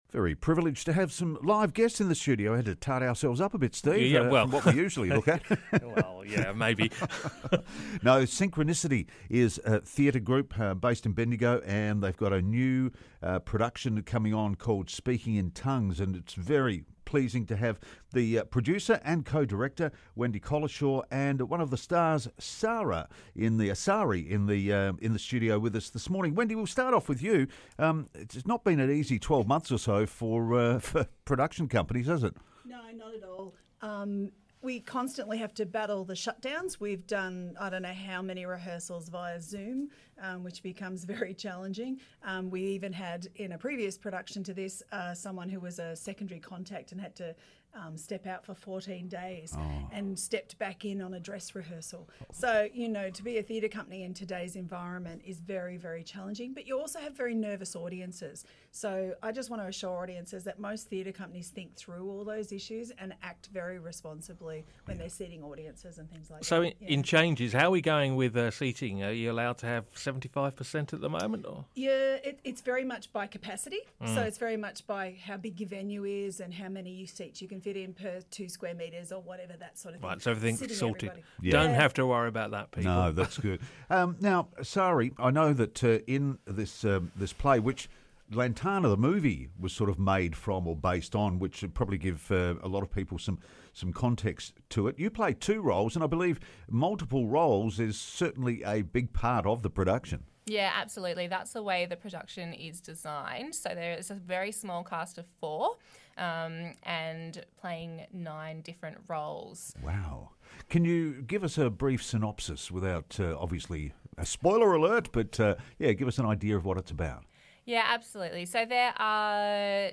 came into the studio to chat about it